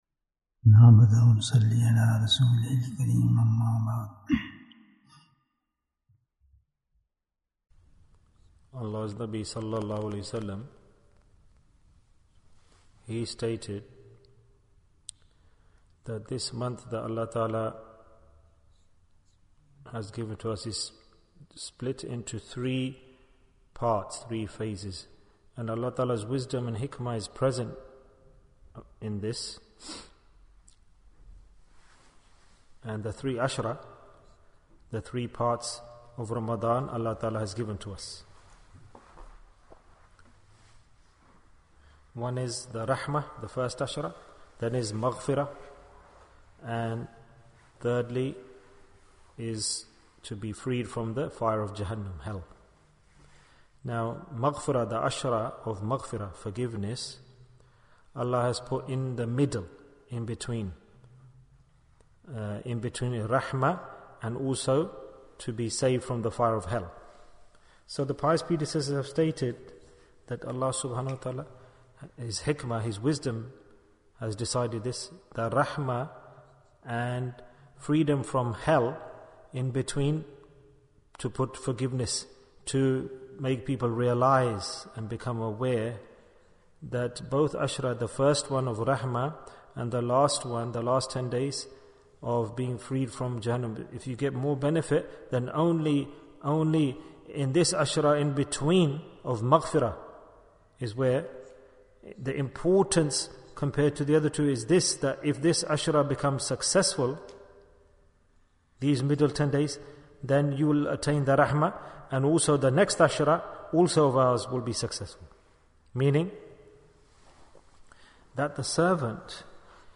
Bayan, 23 minutes3rd April, 2023